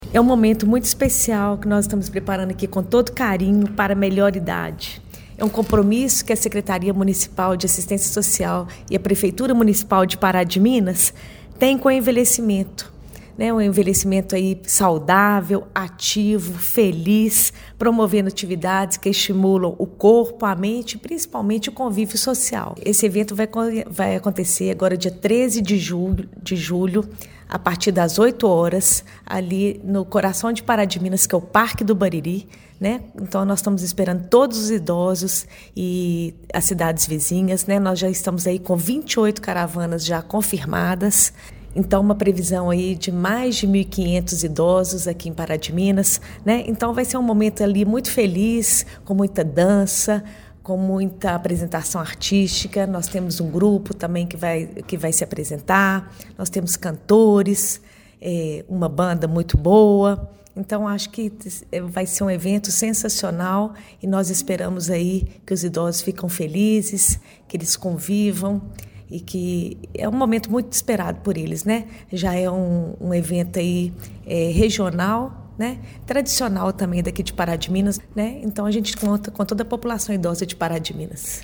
Cláudia Assunção Faria, Secretária Municipal de Assistência e Desenvolvimento Social, destaca o compromisso da administração municipal com a promoção de um envelhecimento saudável, ativo e feliz: